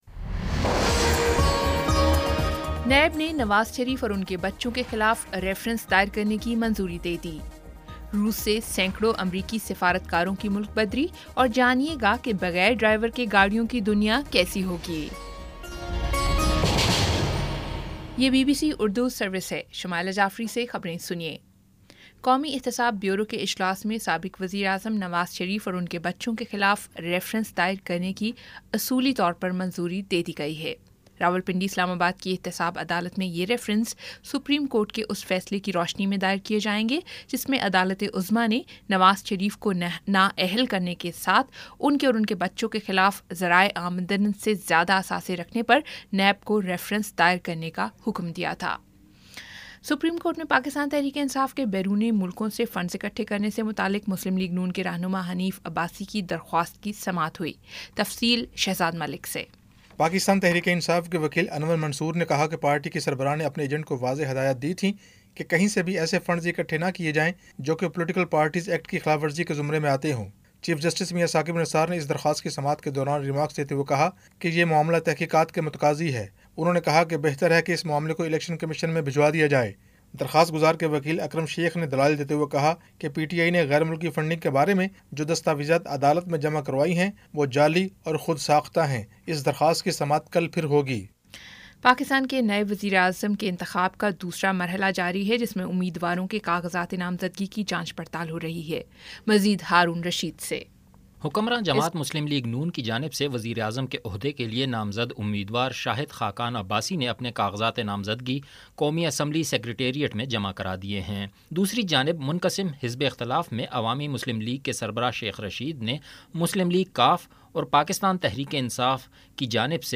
جولائی 31 : شام چھ بجے کا نیوز بُلیٹن